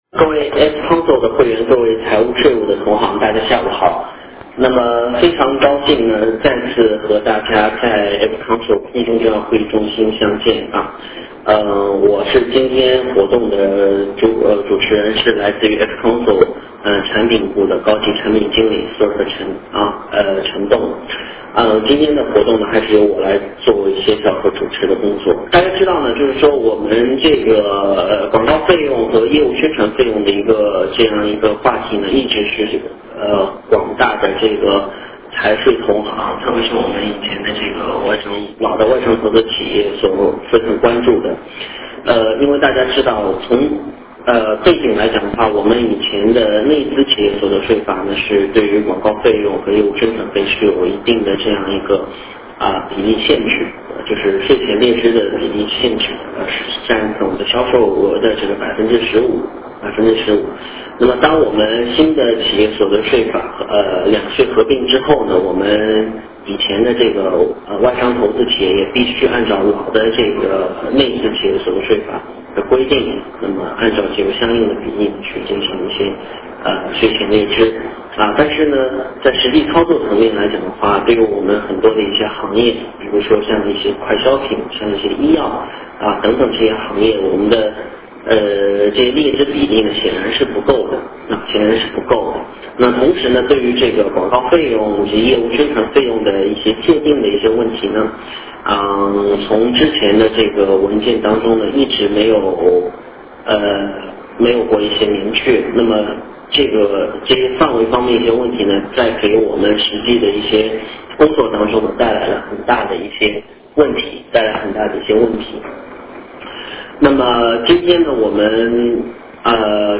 形式：电话会议
谁应该参与 外商投资企业财务/税务总监、经理 谁来主讲 财政部税政司所得税处副处长，参与09年众多“财税字”所得税文件的制定，拥有15年以上的所得税行政管理经验 活动流程： 17：00-18：00 广告费和业务宣传费所得税最新政策的解读 权威比较广告费和业务宣传费新老政策范围界定标准 30%的抵扣比例是否存在限制条件？